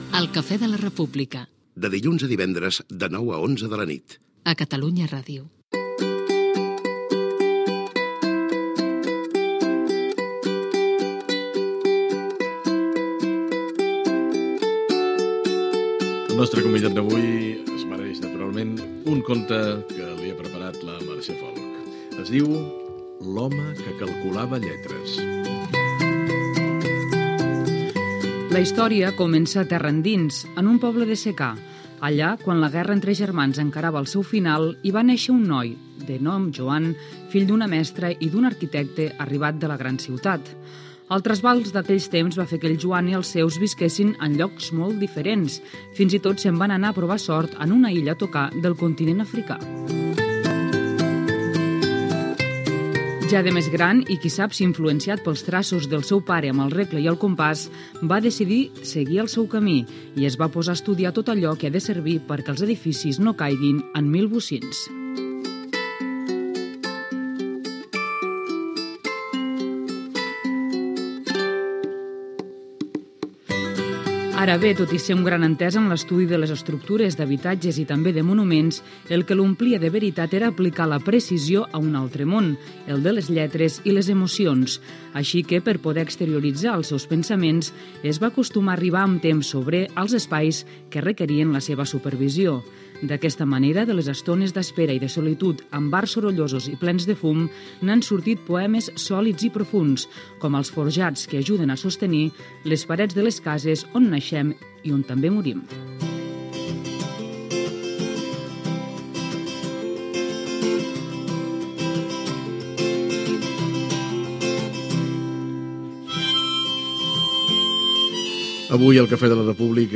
Indicatiu del programa, lectura del conte "L'home que calculava lletres", entrevista al poeta i arquitecte Joan Margarit
Info-entreteniment